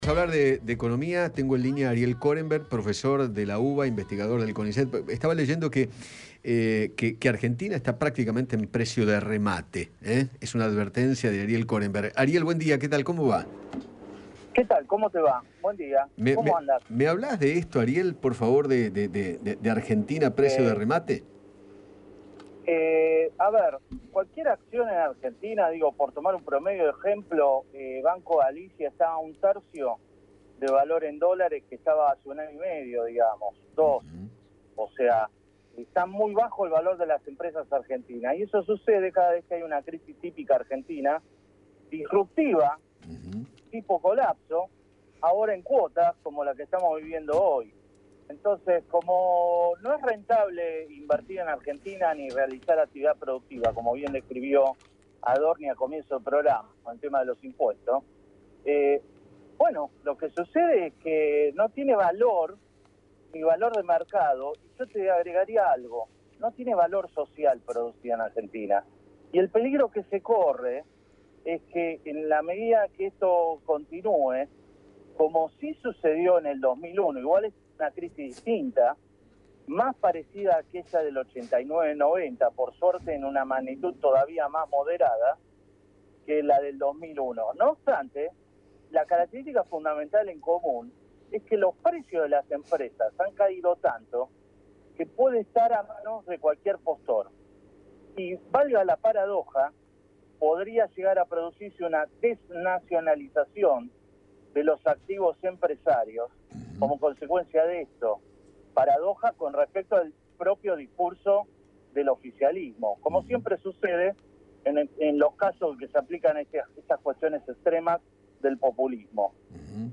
dialogó con Eduardo Feinmann sobre el descenso del precio de las acciones en el país en el último año y medio